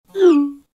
Звуки исходящего и входящего смс в переписке айфон.
2. Звук полученного (входящего) сообщения iPhone
iphone-receive-w.mp3